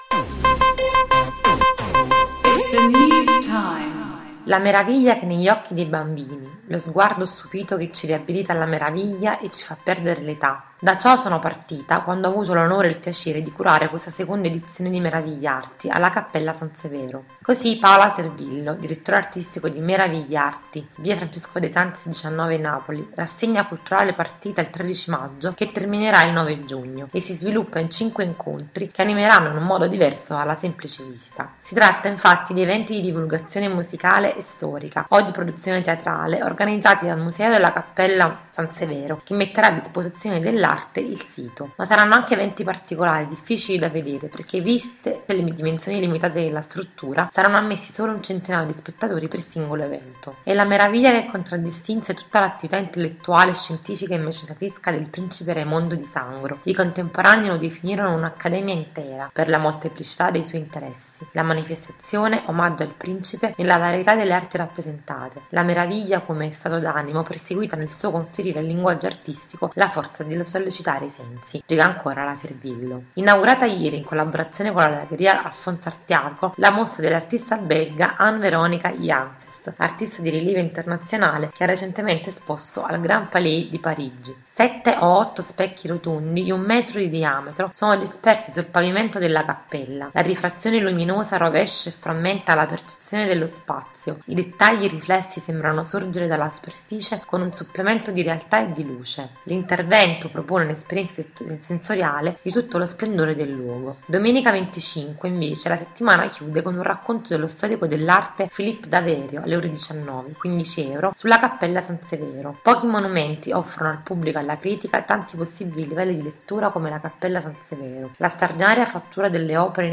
Se vuoi ascoltare l’articolo letto dalle nostre redattrici clicca qui